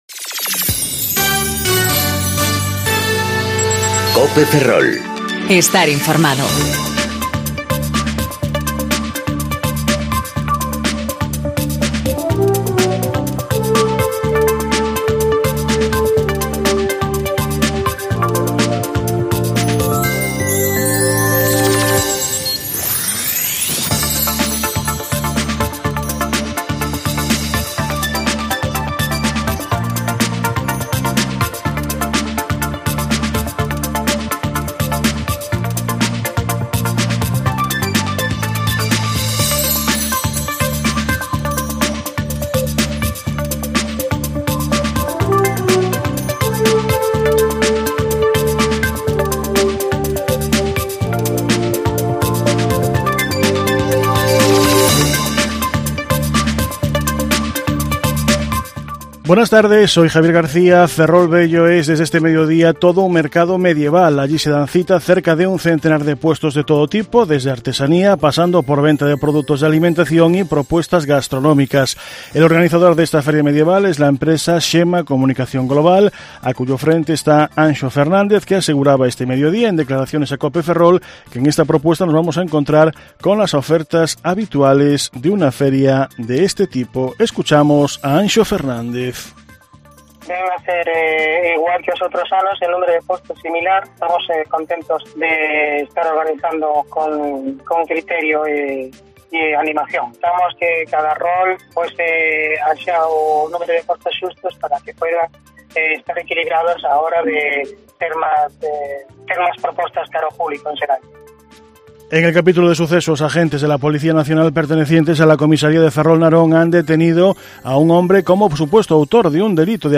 Informativo Mediodía Cope Ferrol 2/8/2019 (De 14.20 a 14.30 horas)